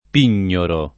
pignoro [ p & n’n’oro ; non -n’ 0 - ]